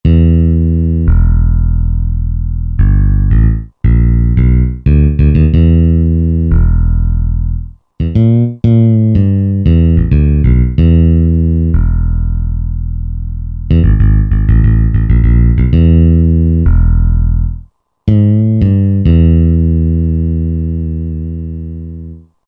Roland S-760 audio demos